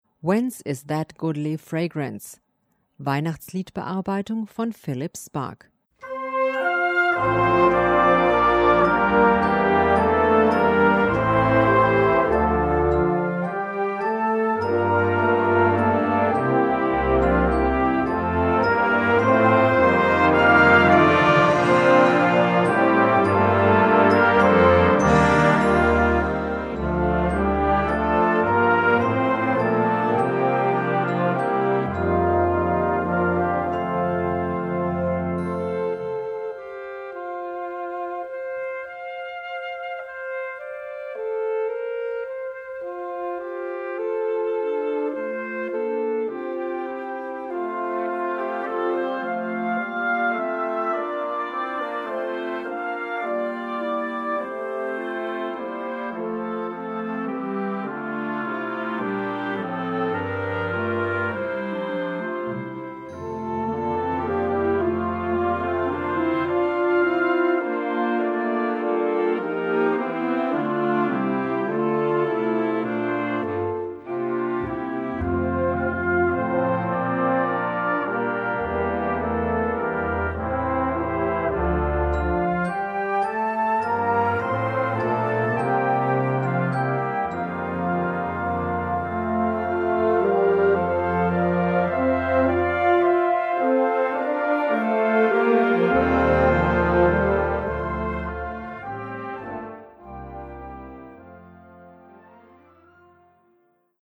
Besetzung: Blasorchester
Die strahlend fröhliche und festliche Melodie
Blasorchester